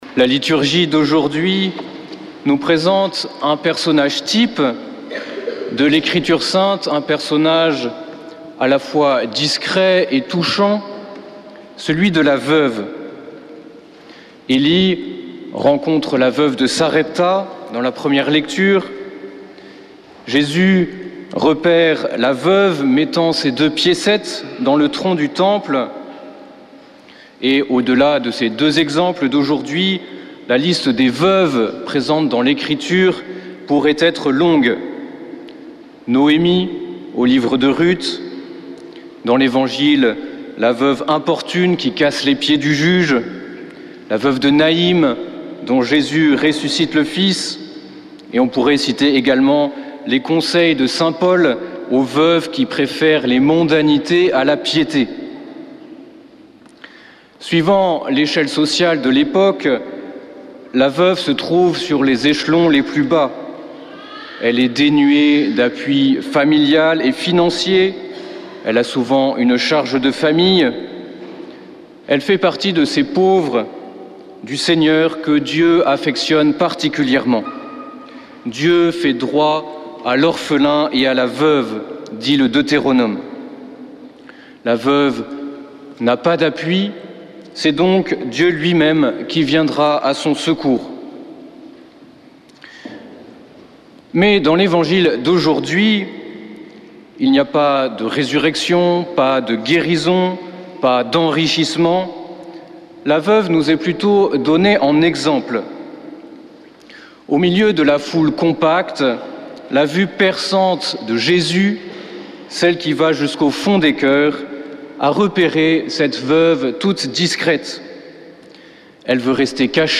dimanche 10 novembre 2024 Messe depuis le couvent des Dominicains de Toulouse Durée 01 h 30 min